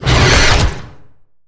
Sfx_creature_squidshark_chase_os_04.ogg